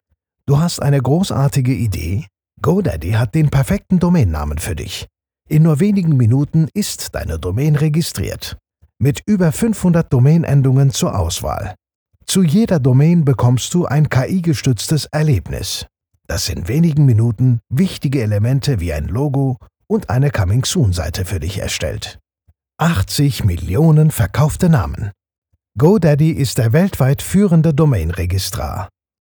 Never any Artificial Voices used, unlike other sites. All our voice actors are premium seasoned professionals.
Best Male Voice Over Actors In March 2026
Turkish
Adult (30-50)